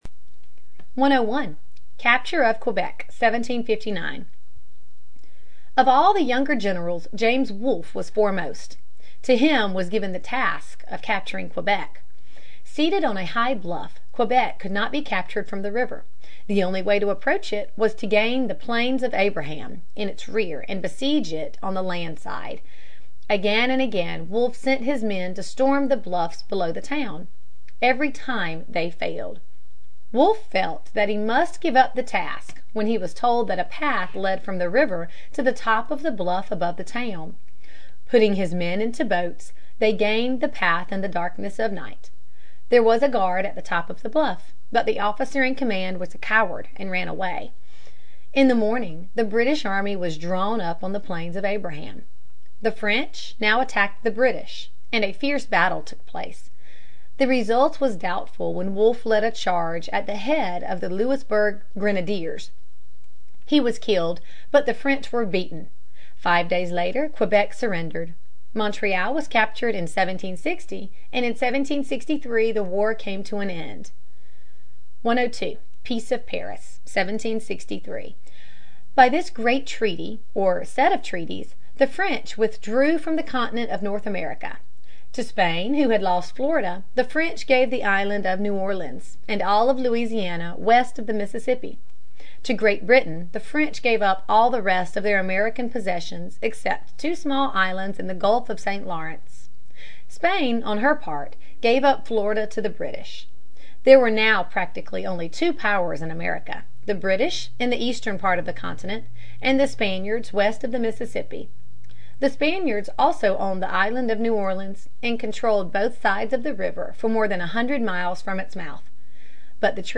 在线英语听力室美国学生历史 第33期:驱除法国人(5)的听力文件下载,这套书是一本很好的英语读本，采用双语形式，配合英文朗读，对提升英语水平一定更有帮助。